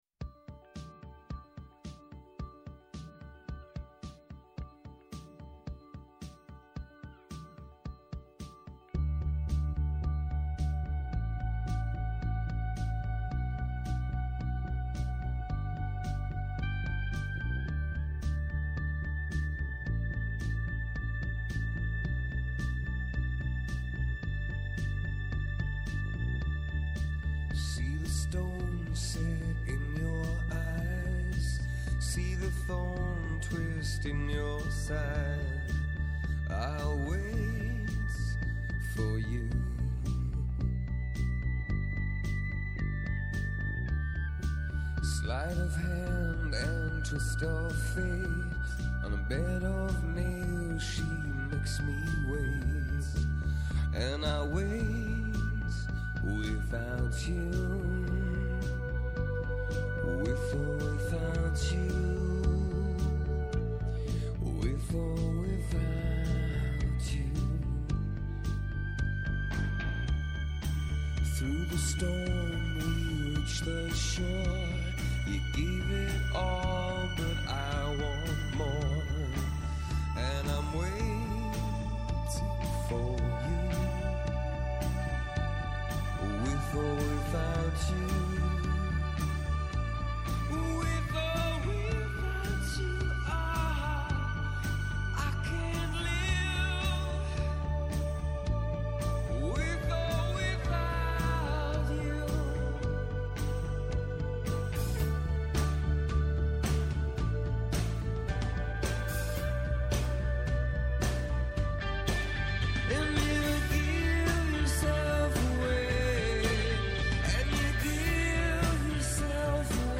Πόση επικαιρότητα μπορεί να χωρέσει σε μια ώρα; Πόσα τραγούδια μπορούν να σε κάνουν να ταξιδέψεις;